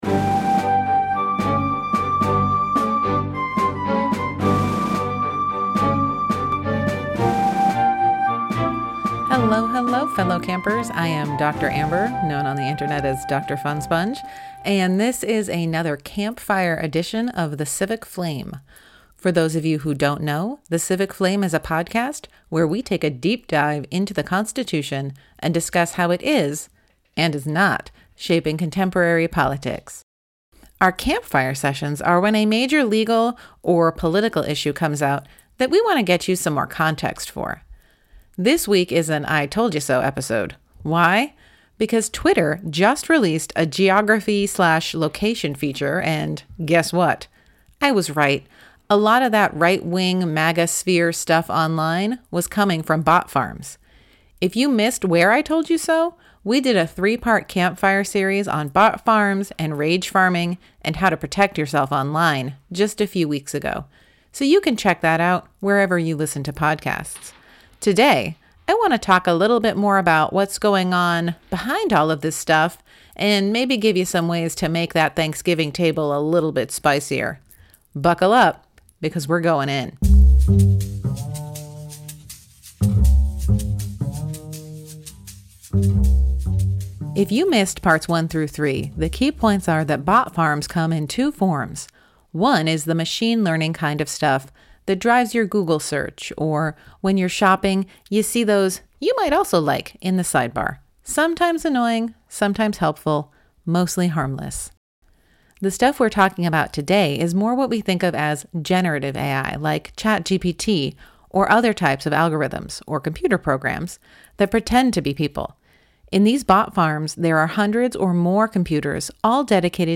This week's campfire is a quick look at what's behind the geo-location kerfuffle with Elon Musk's Twitter and how all the MAGA folks woke up to realize a lot of their online friends were actually bots from international psy-ops. Plus, if you listen closely you can hear my earrings jingling in the background.